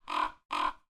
Creature Feature Raven Sound Effect
creature-feature-raven-sound-effect.wav